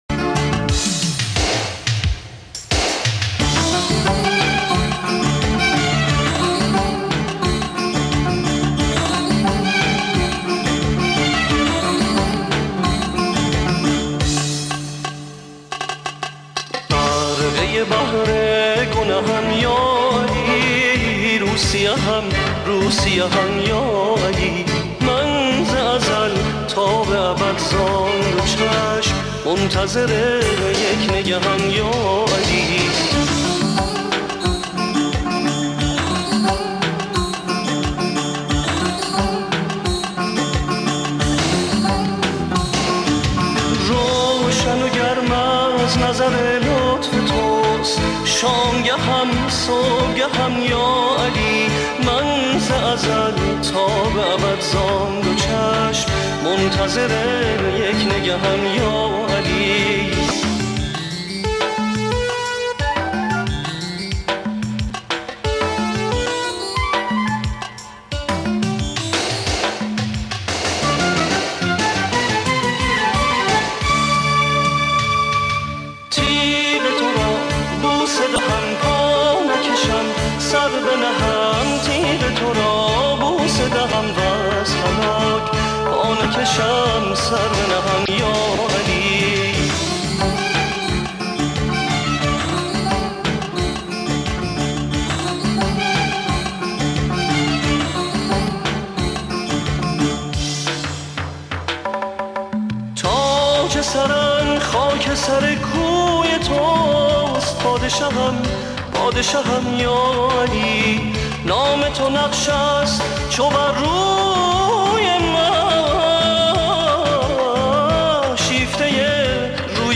бо садои хонандаи эронӣ